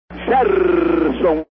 Esse "r" final era repetido à exaustão.
Geraldo José de Almeida fala "Gerson"